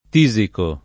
tisico [ t &@ iko ]